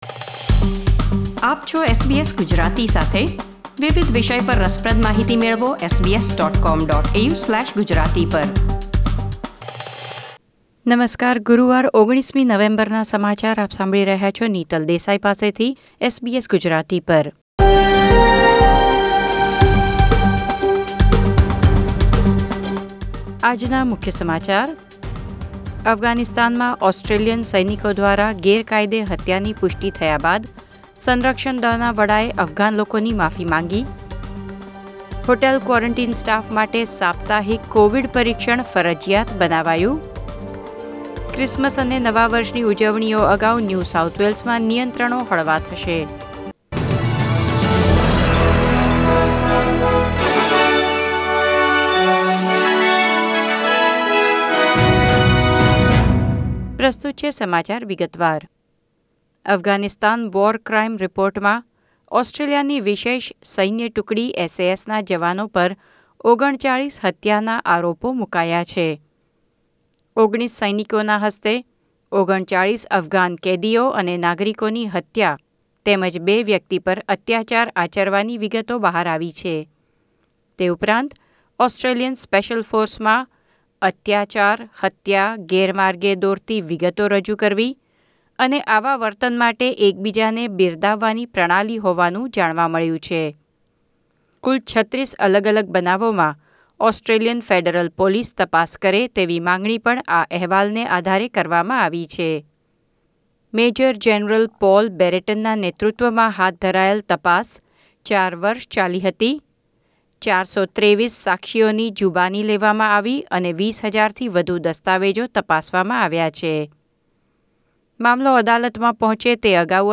SBS Gujarati News Bulletin 19 November 2020